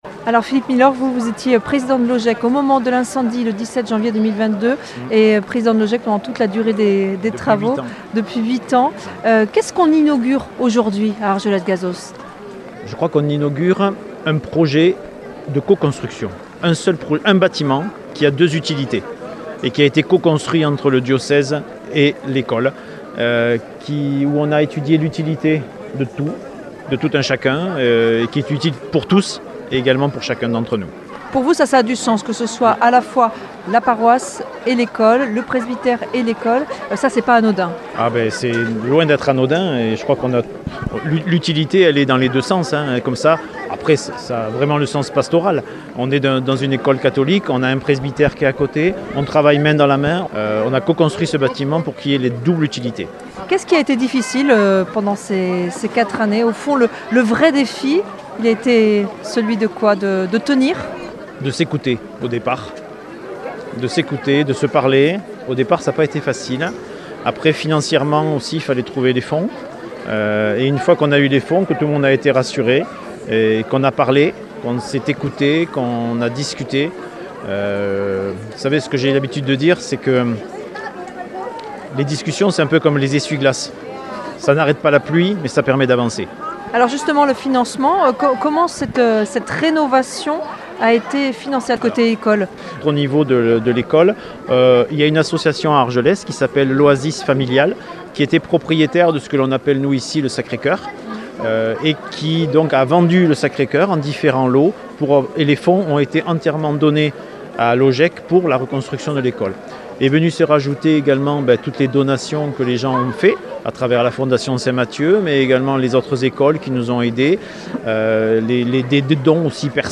à l'occasion de l'inauguration de l'école ND et du presbytère d'Argeles-Gazost. Un evenement après l'incendie devastateur de 2022.